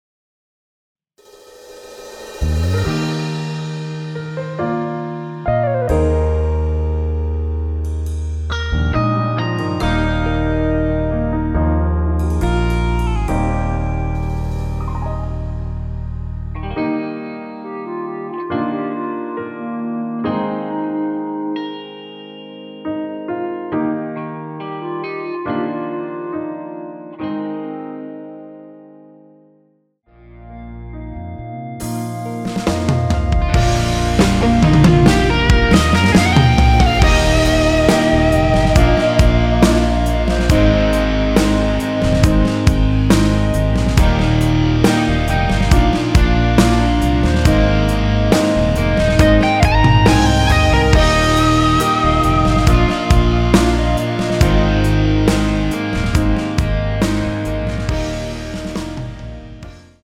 원키에서(-1)내린 멜로디 포함된 MR입니다.(미리듣기 확인)
Eb
앞부분30초, 뒷부분30초씩 편집해서 올려 드리고 있습니다.